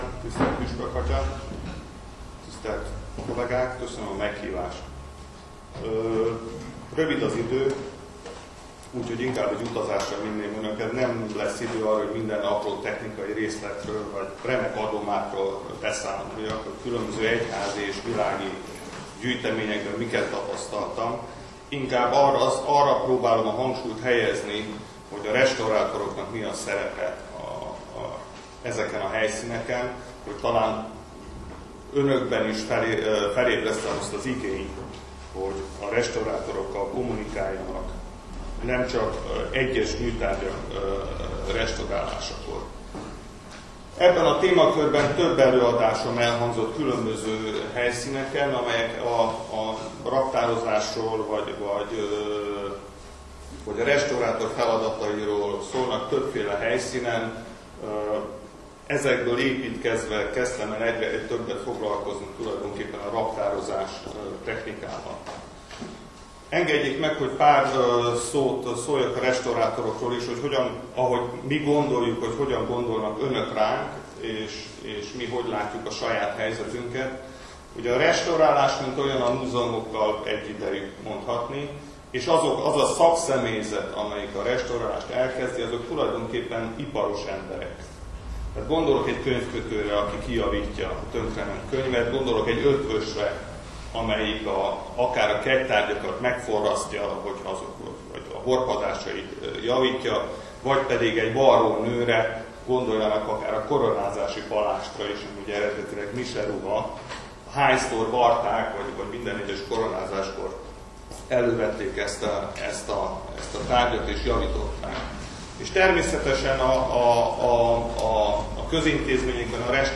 (Az el�ad�sok meghallgathat�k illetve let�lthet�k)